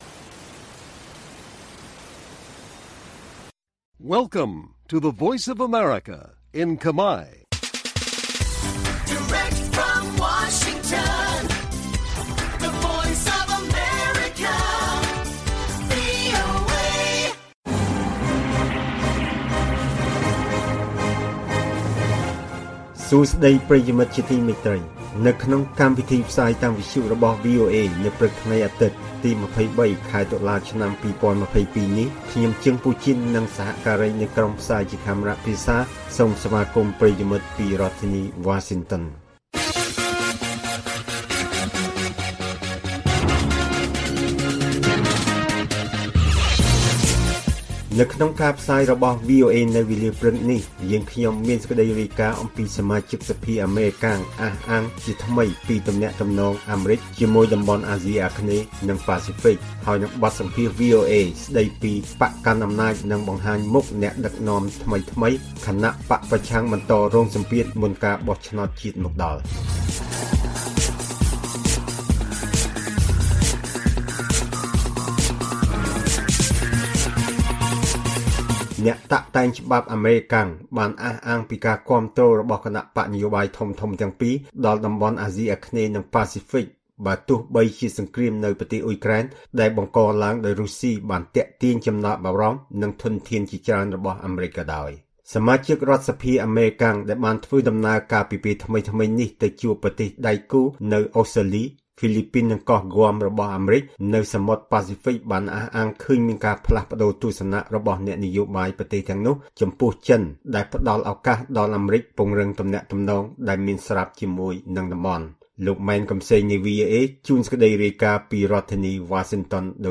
ព័ត៌មាននៅថ្ងៃនេះមានដូចជា សមាជិកសភាអាមេរិកអះអាងជាថ្មីពីទំនាក់ទំនងអាមេរិកជាមួយតំបន់អាស៊ីអាគ្នេយ៍និងប៉ាស៊ីហ្វិក។ បទសម្ភាសន៍ VOA៖ បក្សកាន់អំណាចនឹងបង្ហាញមុខអ្នកដឹកនាំថ្មីៗ ខណៈបក្សប្រឆាំងបន្តរងសម្ពាធ មុនការបោះឆ្នោតជាតិមកដល់និងព័ត៌មានផ្សេងៗទៀត៕